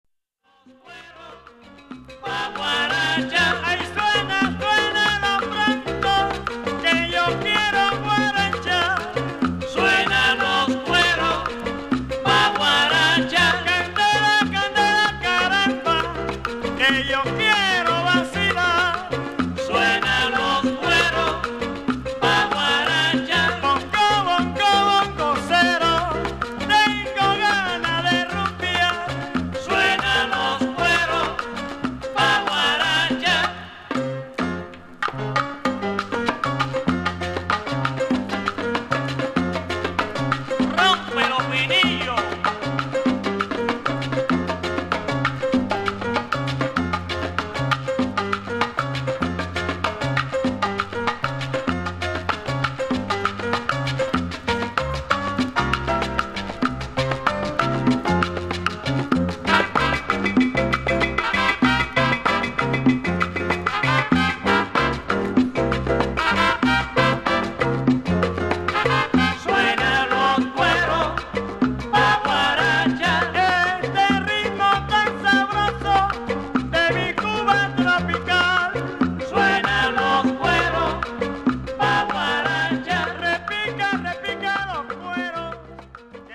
本作品は後期のGUARACHA , CUMBIAなどダンサブルな楽曲で構成されている。